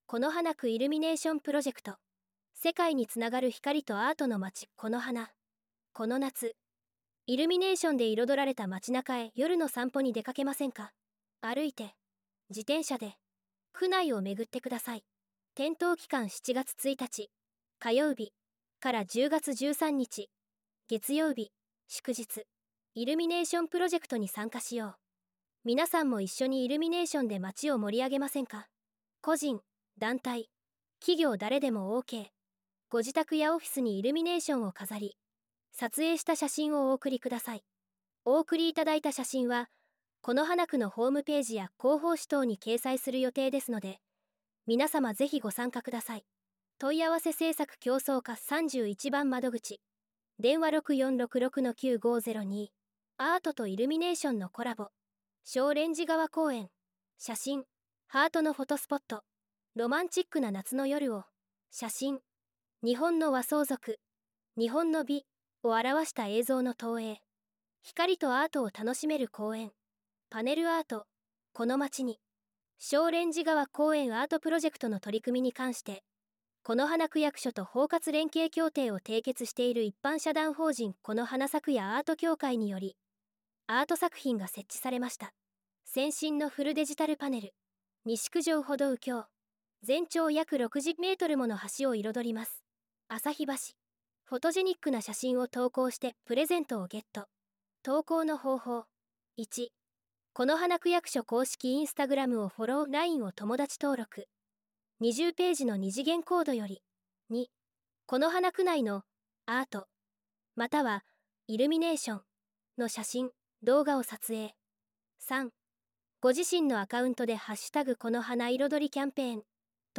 音声版　広報「このはな」令和7年7月号